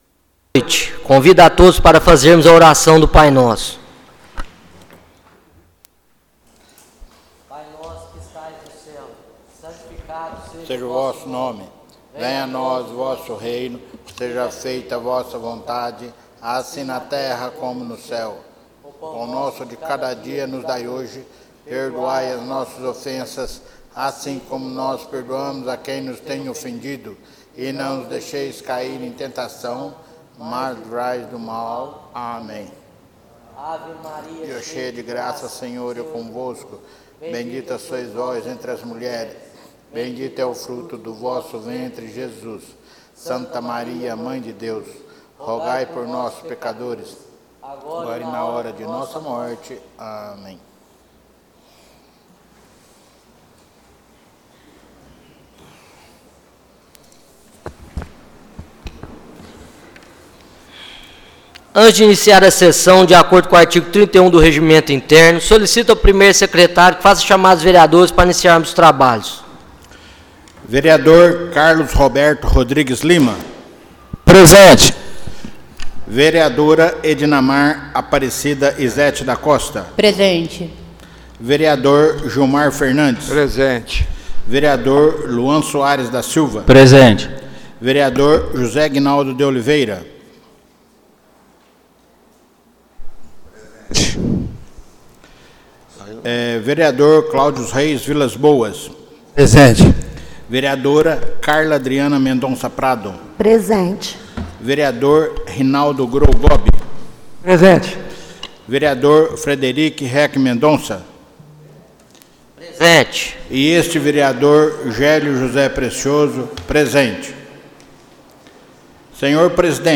Áudio das sessões — Câmara Municipal de Igarapava - SP